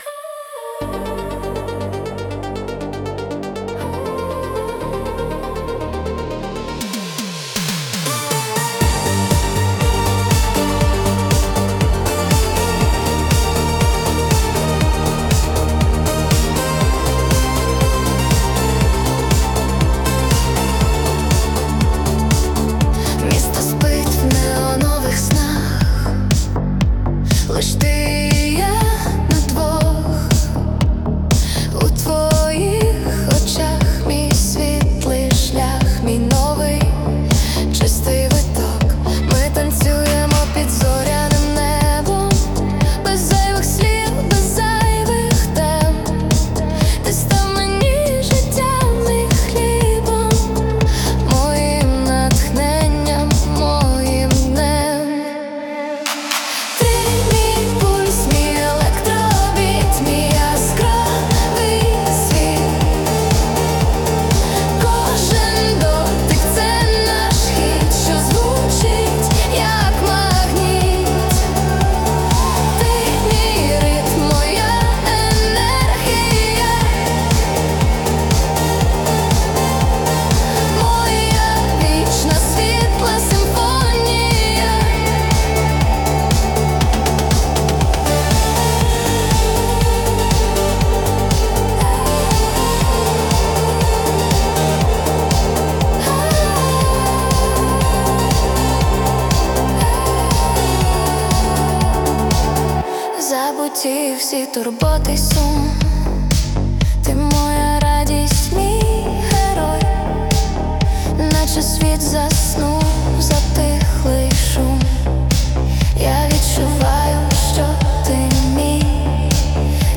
🎵 Жанр: Synth-pop / Electro-Dance